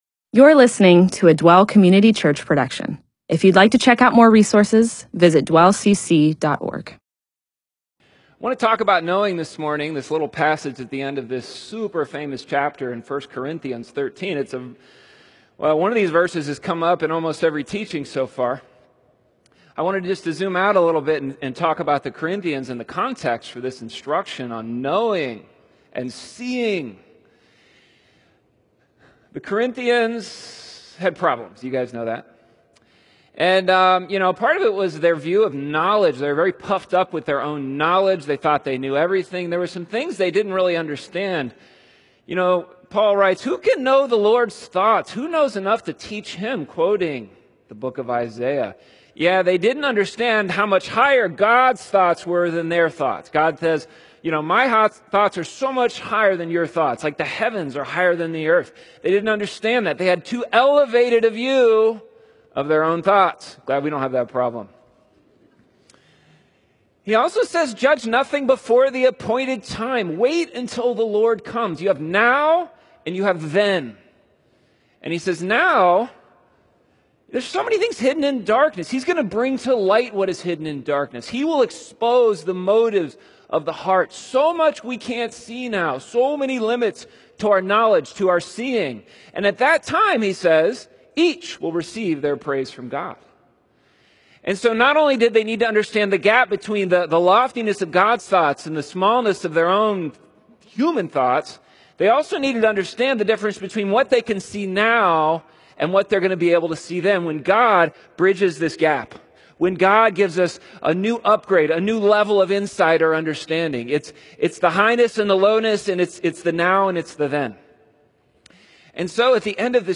MP4/M4A audio recording of a Bible teaching/sermon/presentation about 1 Corinthians 13:9-12.